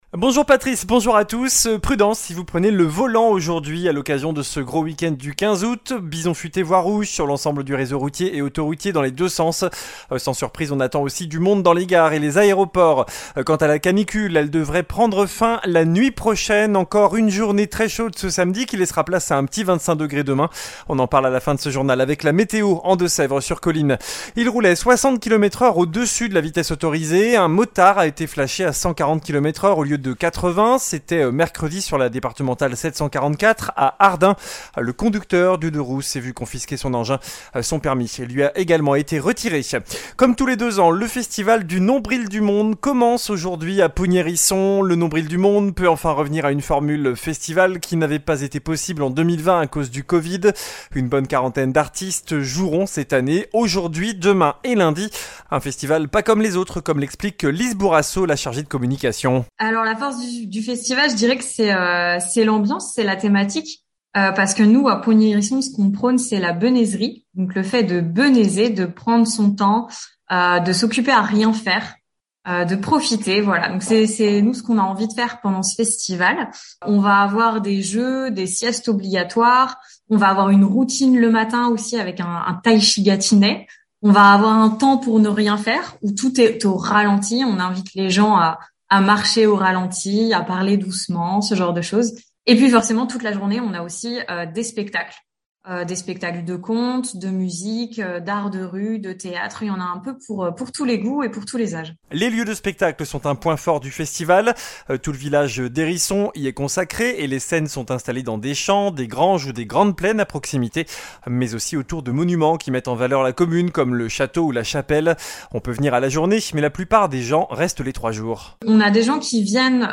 JOURNAL DU SAMEDI 13 AOÛT